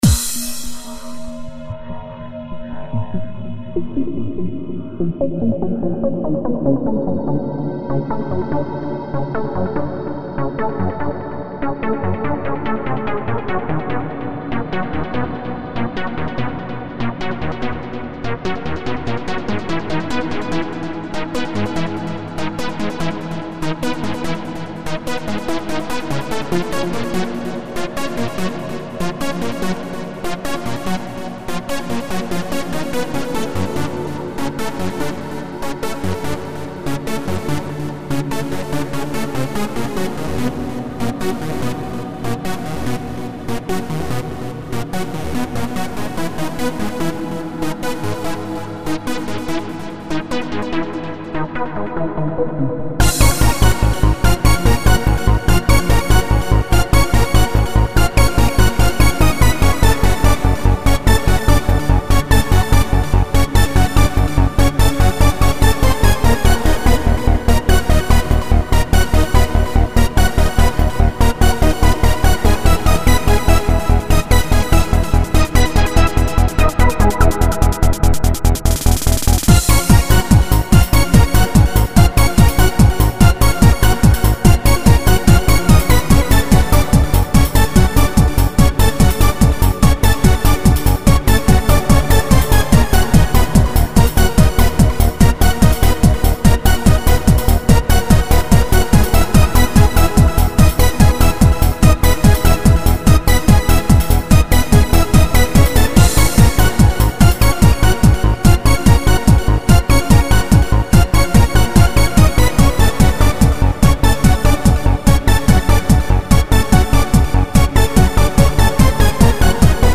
electronic
spacial
Strange style electronic musical sequence.